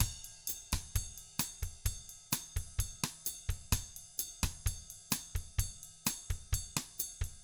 129BOSSAT2-R.wav